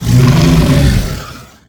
Sfx_creature_snowstalker_distantcall_06.ogg